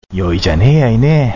医療人のための群馬弁講座；慣用句